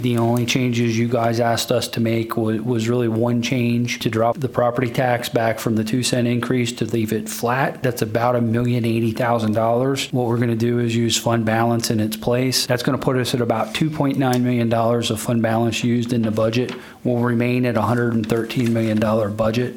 During last night’s Allegany County Commissioners meeting, the county commissioners held a public hearing for the Fiscal Year 2026 budget they will be approving next week on Thursday, May 29.  County Administrator Jason Bennett relayed how the county will compensate for deciding not to increase property taxes…